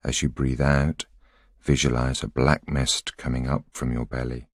a voice sample is a single voiceover statement which can be used to compose voice collections.